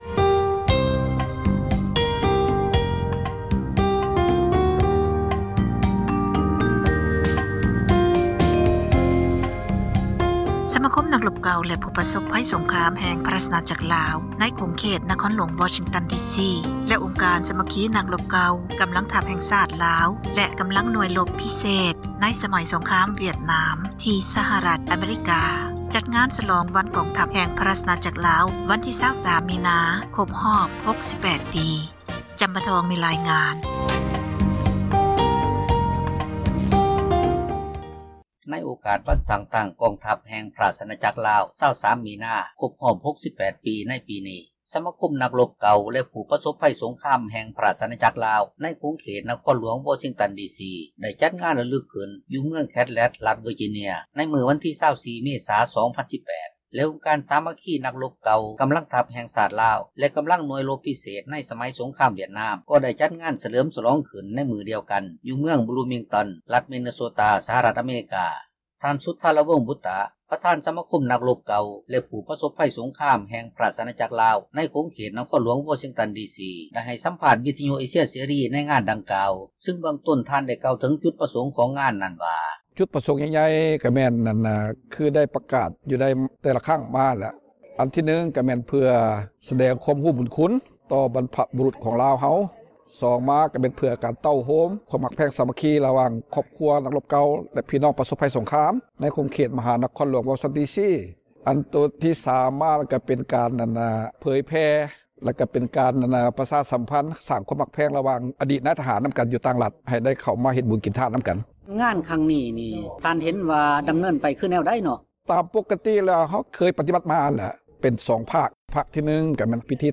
(ສຽງສັມພາດ)